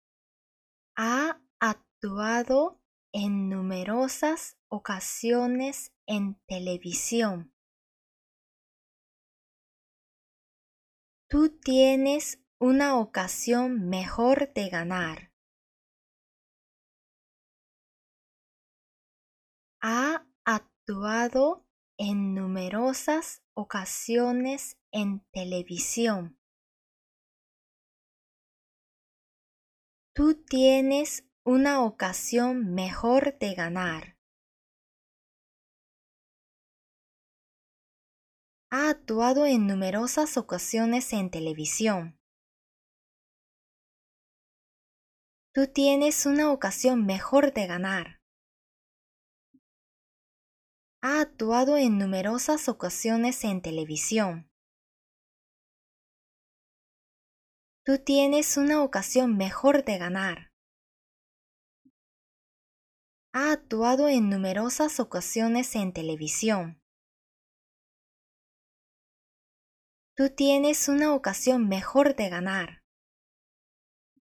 それでは、上記の２つの例文をシャドーイングしてみましょう！